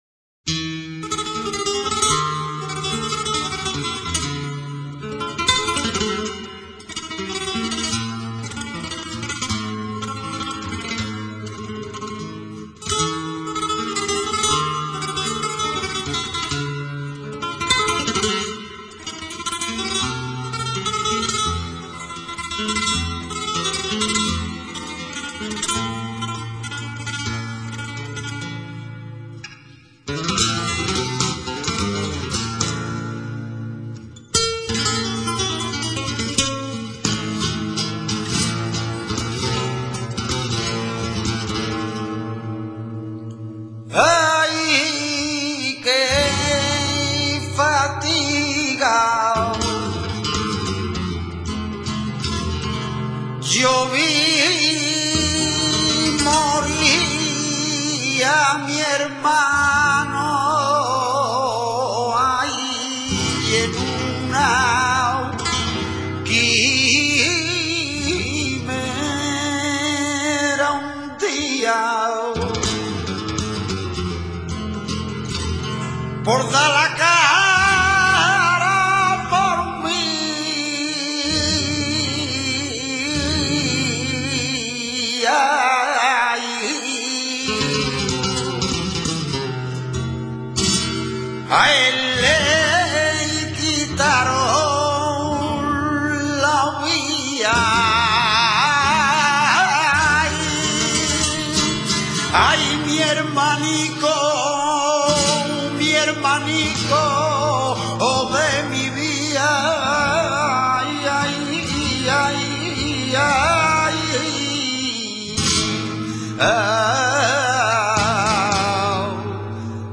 TARANTO. M. [De taranta.] Cante similar a la taranta, o modalidad de ella, de la que se distingue por obedecer su toque a la tendencia acompasada de la zambra, ejecutado en el mismo tono que la taranta y la cartagenera.
taranto.mp3